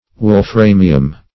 Search Result for " wolframium" : The Collaborative International Dictionary of English v.0.48: Wolframium \Wol*fra"mi*um\, n. [NL.